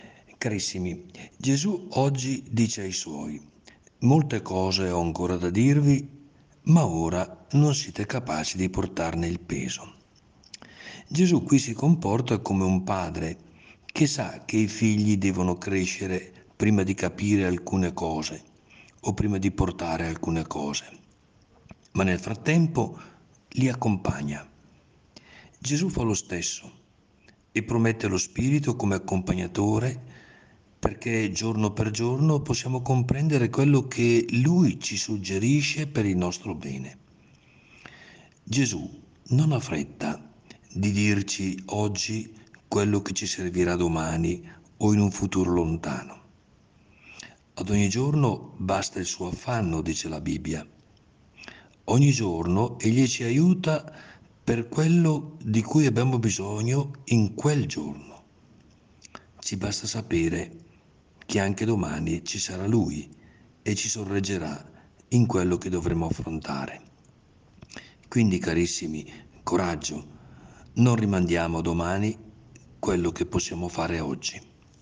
PODCAST – Prosegue la rubrica podcast “In ascolto della Parola”, curata da Mons. Carlo Bresciani, vescovo della Diocesi di San Benedetto del Tronto – Ripatransone – Montalto, il quale ci accompagnerà con un contributo quotidiano.
Il Vescovo commenta la Parola di Dio per trarne ispirazione per la giornata.